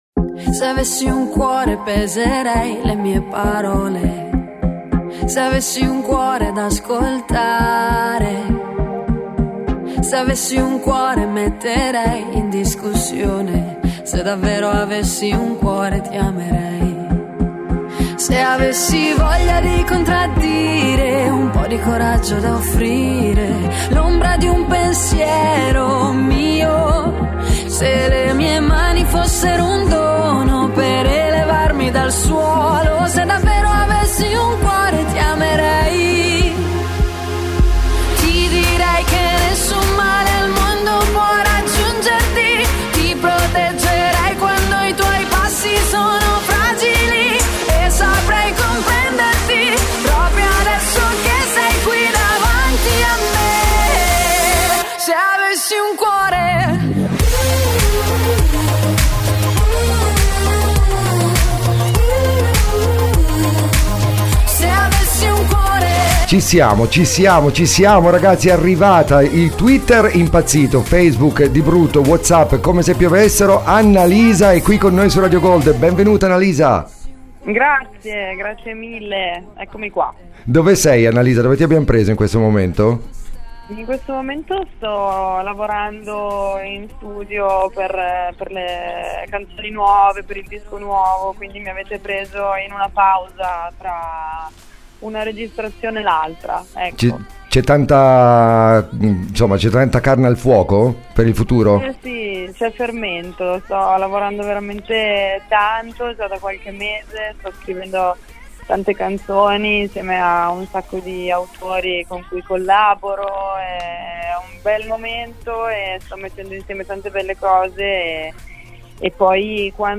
intww_annalisa.mp3